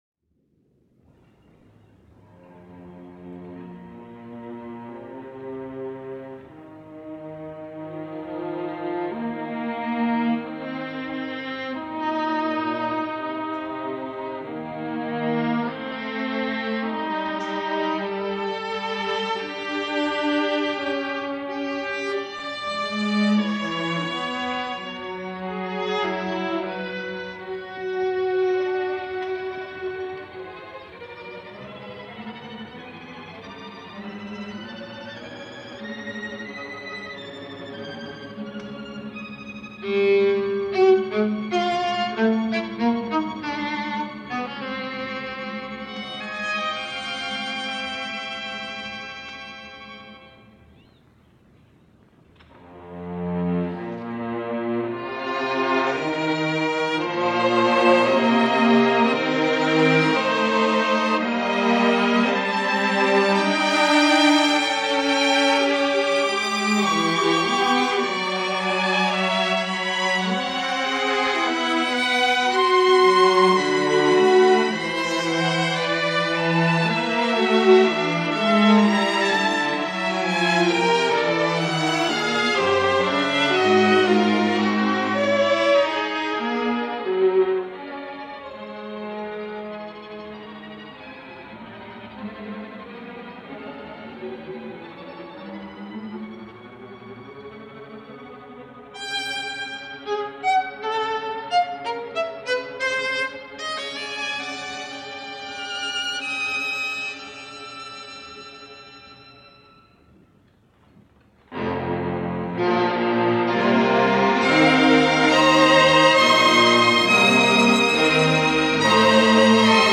studio performance